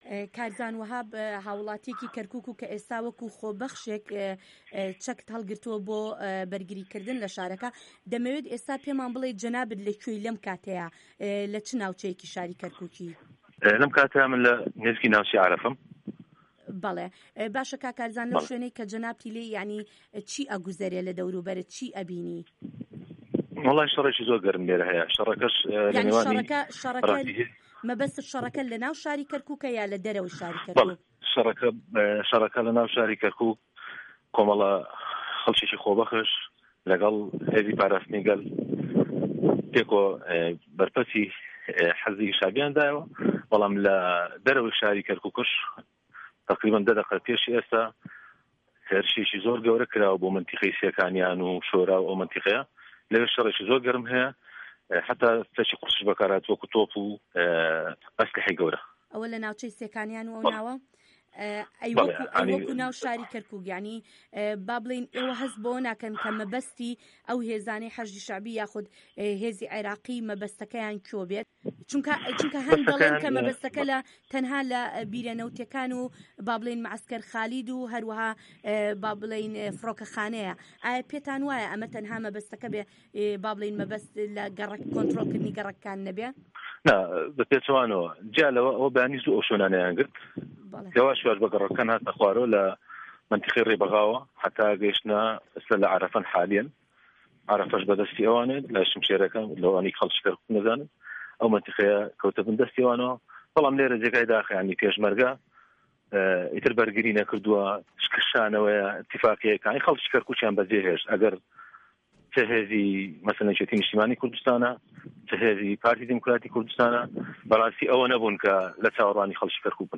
دەقی وتووێژەکە